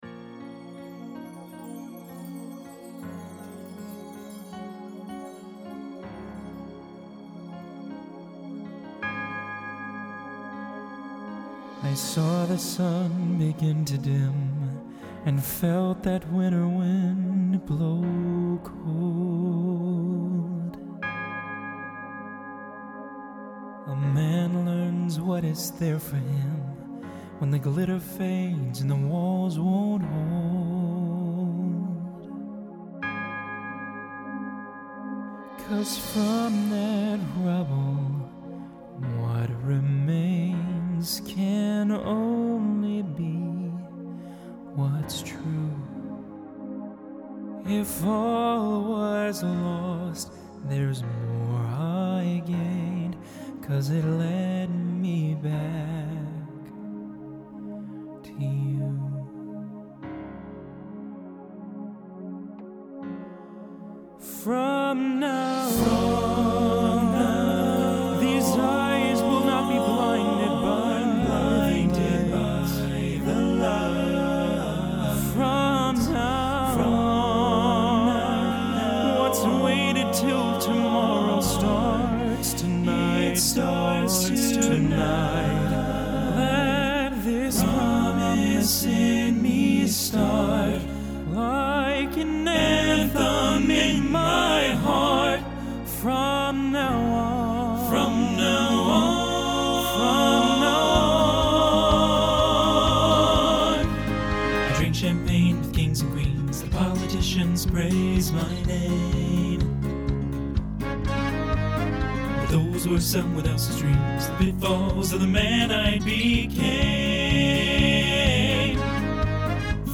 Genre Broadway/Film Instrumental combo
Solo Feature Voicing TTB